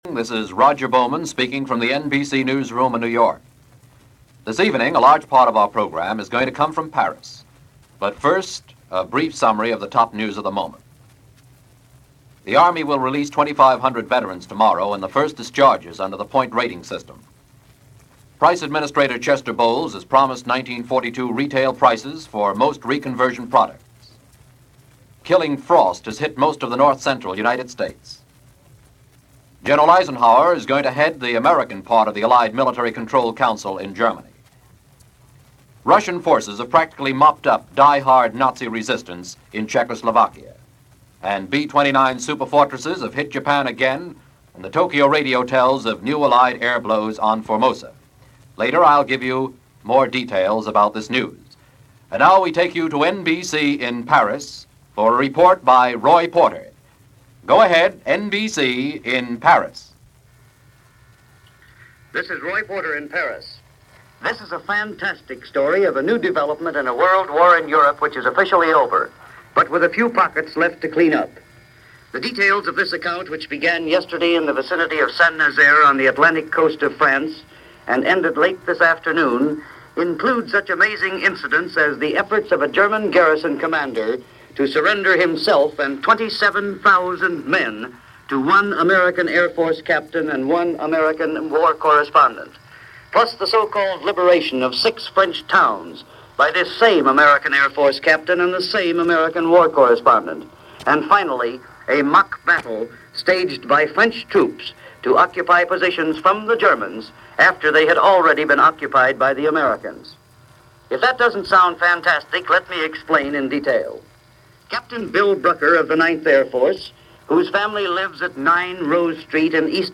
News Of The World – NBC Radio